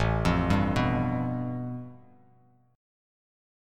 A7sus2#5 Chord
Listen to A7sus2#5 strummed